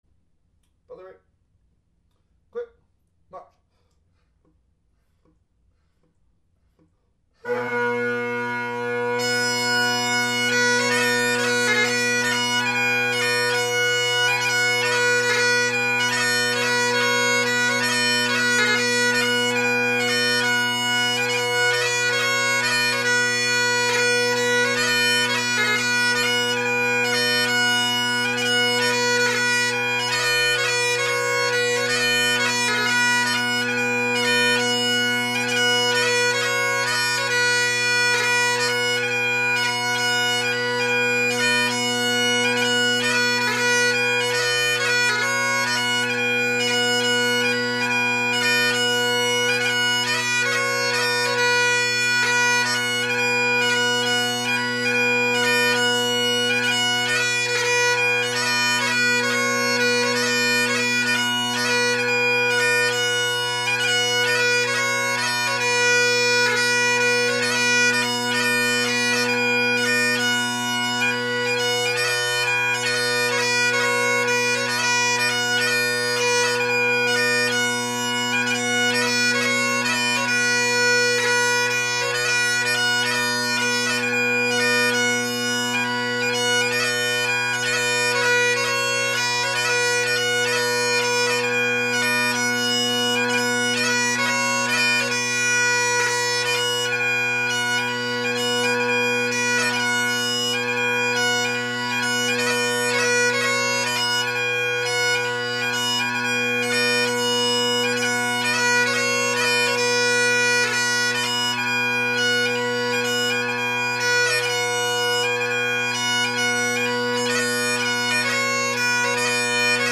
Bagpipe Sound Research
John MacMillan of Barra (with roll off count) – this belongs in the previous post by I slowed waaaayyyyy down so, ya know, not very useful for drummers
john_macmillan_of_barra-drum-tempo_slowed.mp3